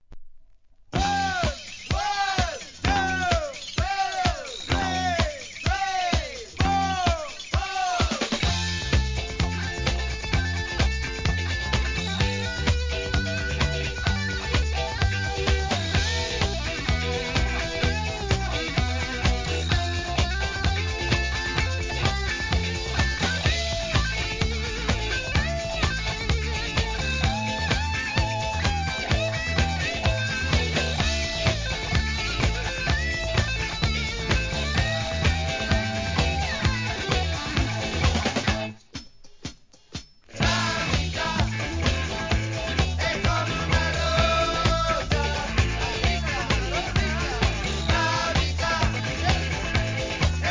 店舗 ただいま品切れ中です お気に入りに追加 1976年の情熱ラテン・ディスコ!!